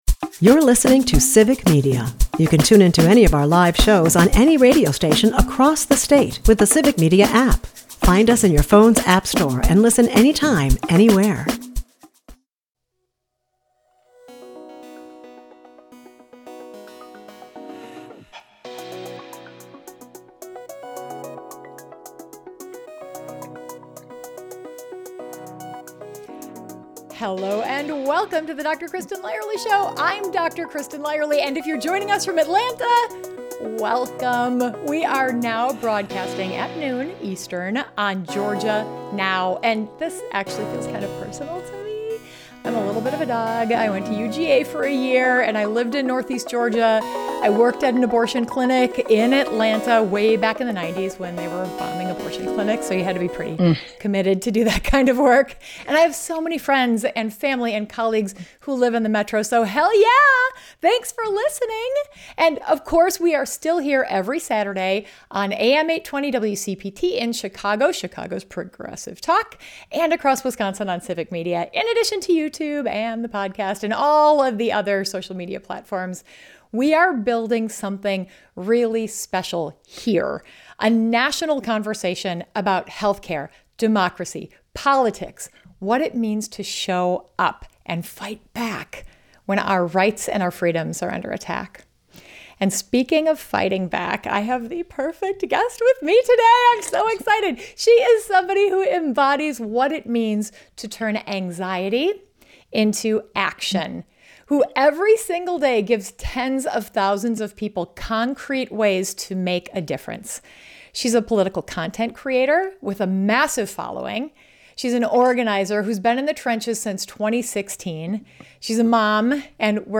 Whether you're a longtime activist or someone who's never made a political phone call, this conversation offers practical tools, inspiring stories, and a roadmap for turning anxiety into impact.